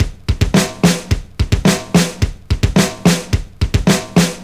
• 108 Bpm Drum Groove F Key.wav
Free drum groove - kick tuned to the F note. Loudest frequency: 1664Hz
108-bpm-drum-groove-f-key-xia.wav